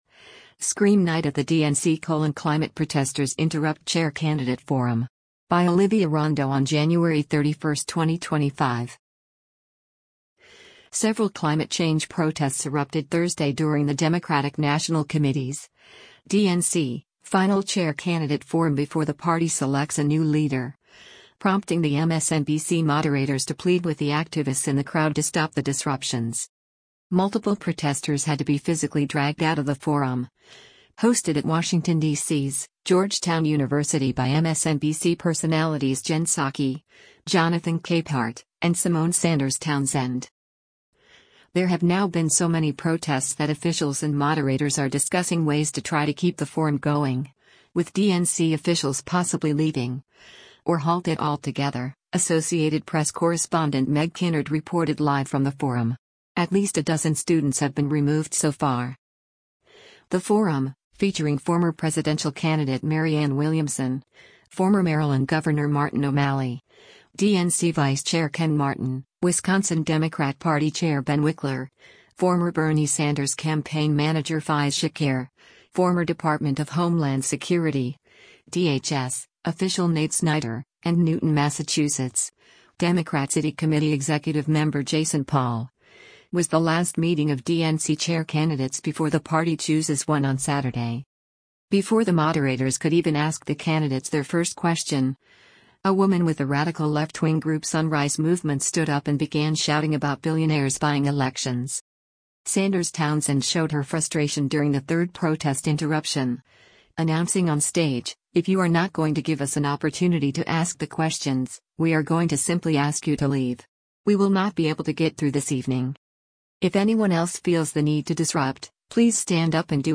Several climate change protests erupted Thursday during the Democratic National Committee’s (DNC) final chair candidate forum before the party selects a new leader, prompting the MSNBC moderators to plead with the activists in the crowd to stop the disruptions.